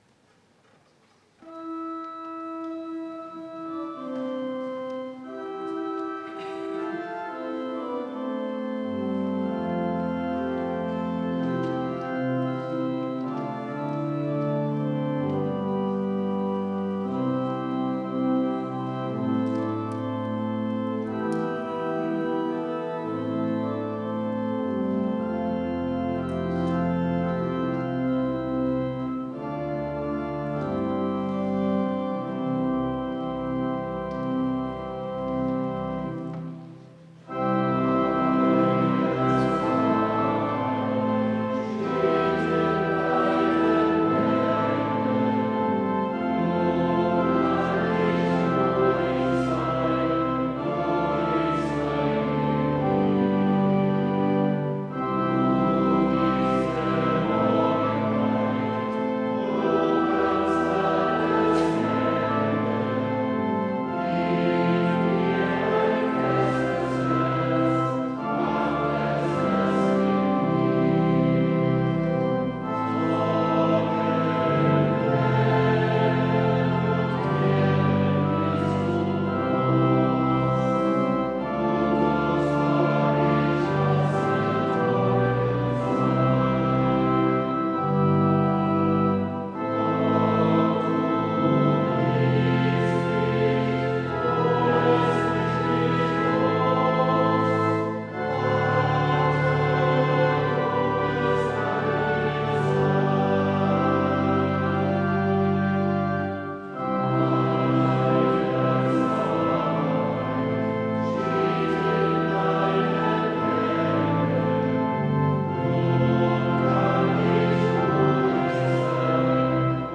Gottesdienst am 23.02.2025
Meine Zeit steht in deinen Händen... (LG 69) Ev.-Luth. St. Johannesgemeinde Zwickau-Planitz